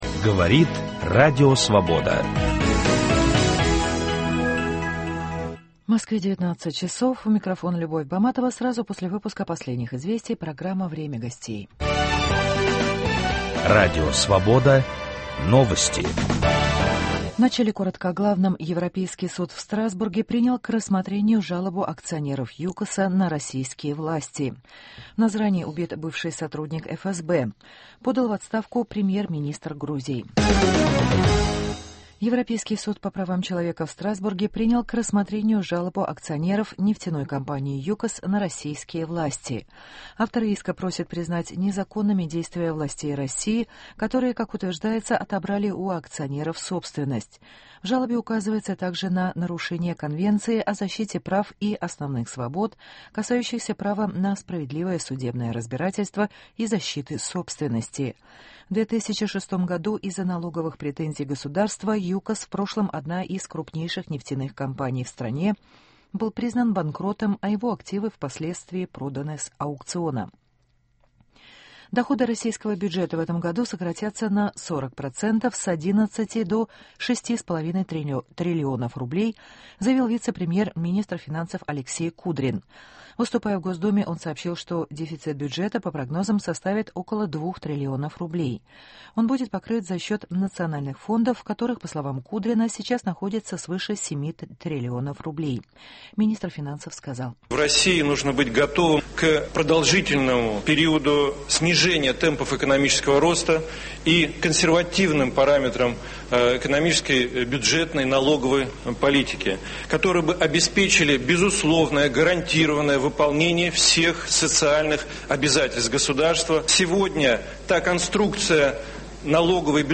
Служба безопасности Украины открывает архивы бывшего КГБ УССР. Ведущий программы Виталий Портников беседует с директором государственного архива СБУ Владимиром Вятровичем